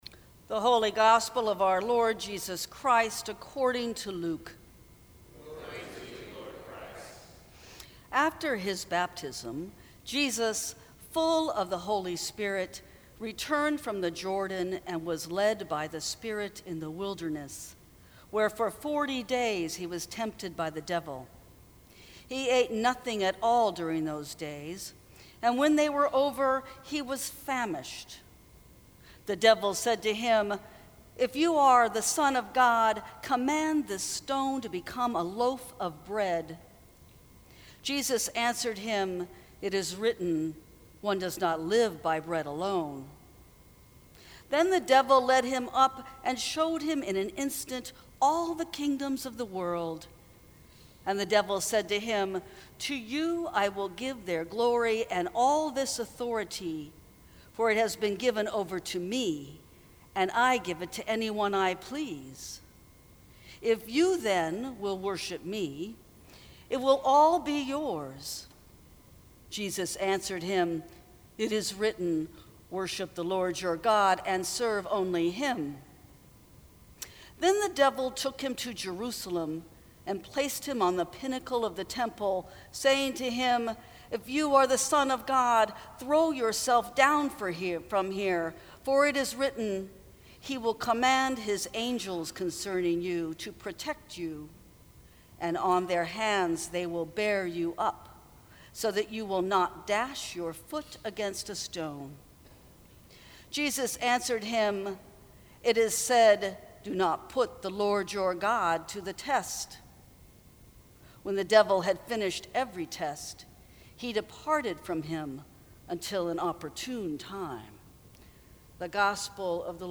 Sermons from St. Cross Episcopal Church Who Who Who Are You?